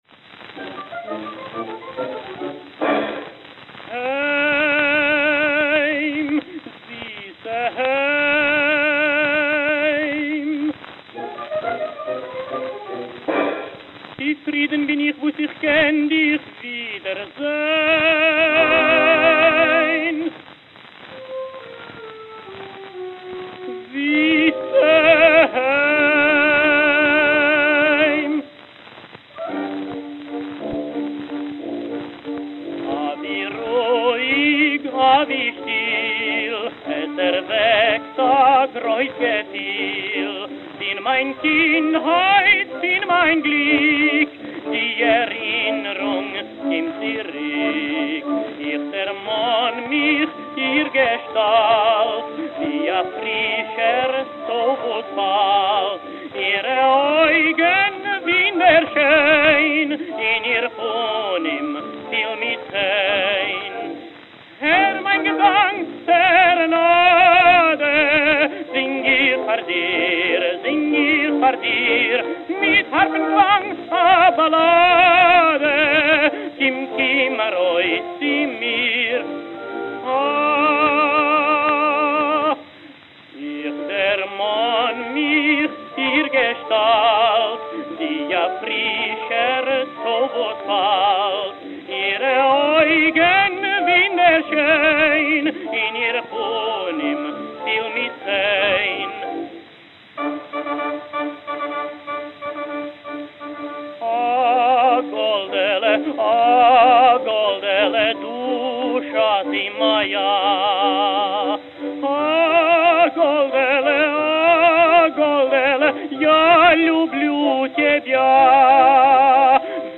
Camden, New Jersey Camden, New Jersey